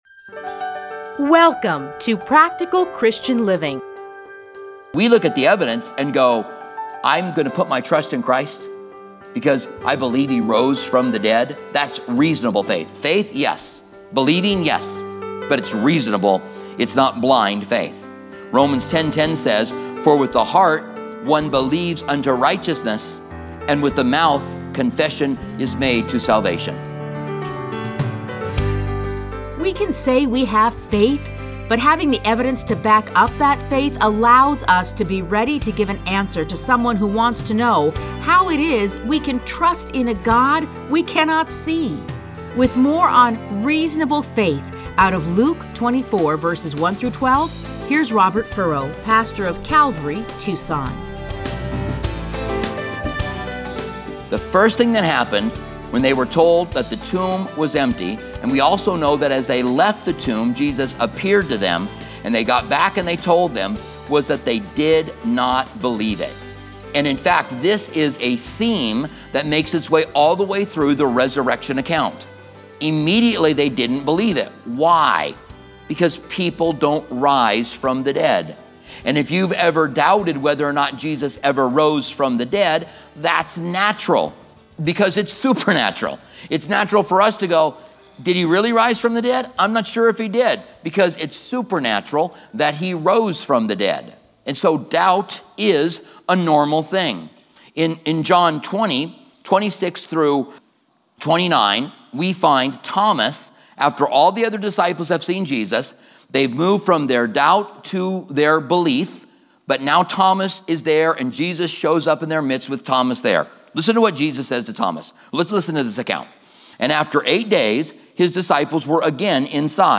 Listen to a teaching from Luke 24:1-12.